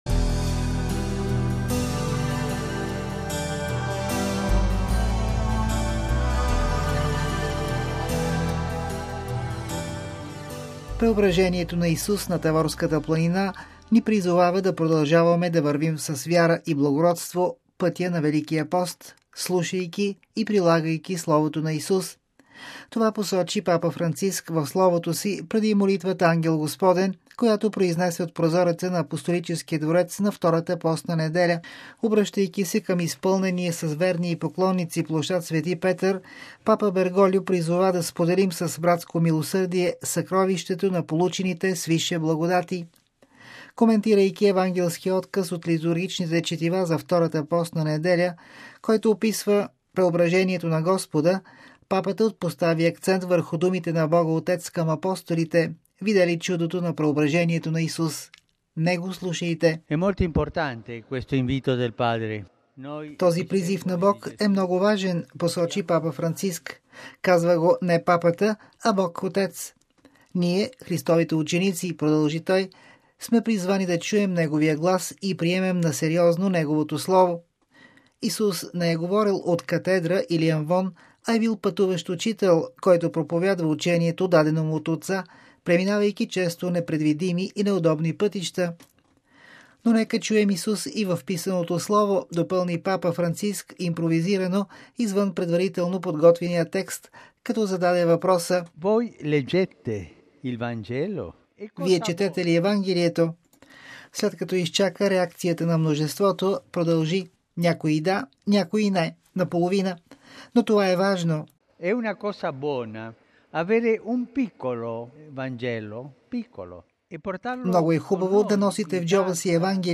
Това посочи Папа Франциск в словото си преди молитвата Ангел Господен, която произнесе от прозореца на Апостолическия дворец на Втората постна неделя.
„Но нека чуем Исус и в писаното слово”, допълни Папата импровизирано, извън предварително подготвения текст, като зададе въпроса: „Вие четете ли Евангелието?”.